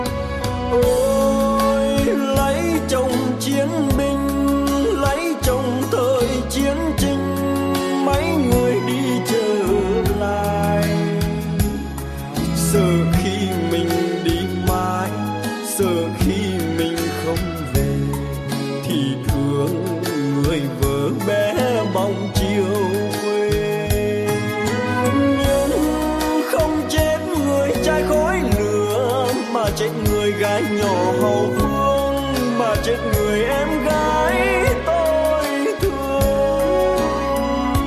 ca khúc Bolero Trữ Tình